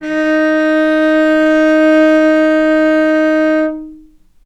vc-D#4-mf.AIF